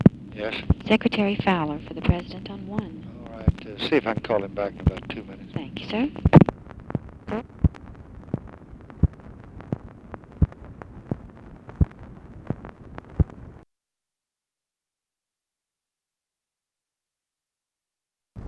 Conversation with OFFICE SECRETARY
Secret White House Tapes